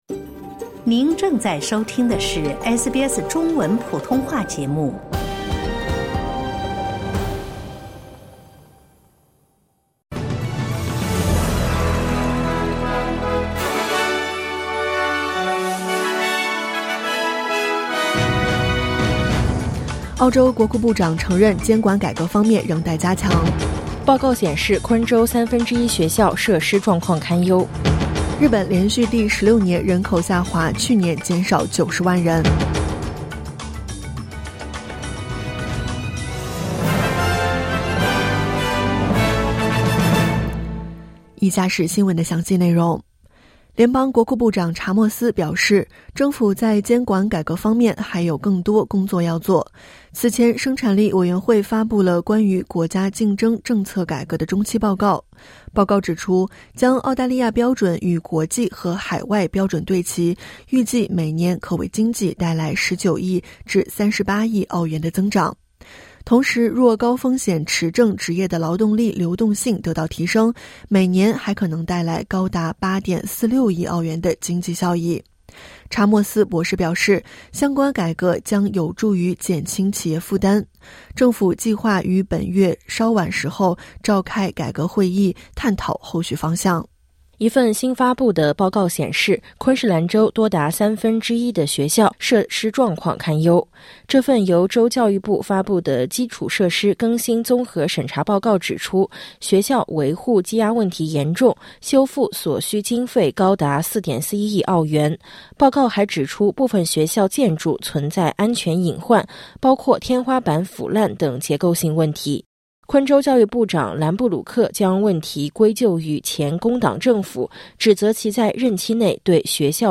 SBS早新闻（2025年8月8日）